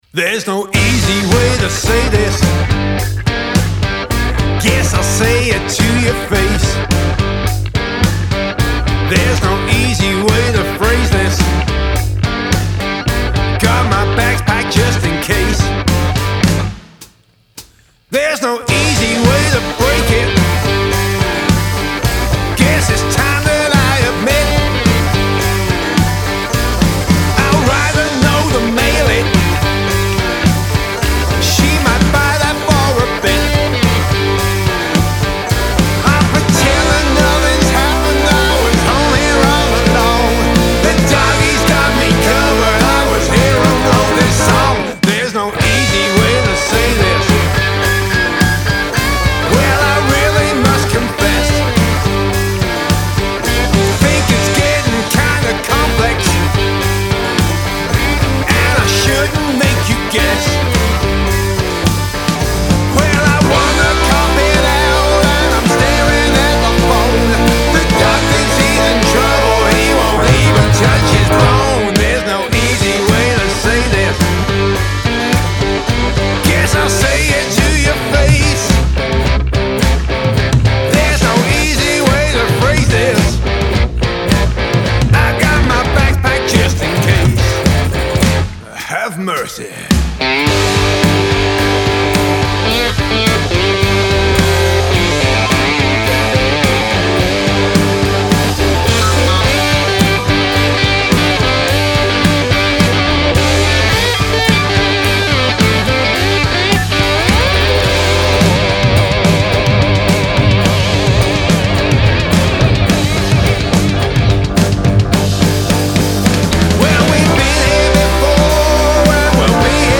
Male Vocal, Guitar, Lap Steel, Banjo, Bass Guitar, Drums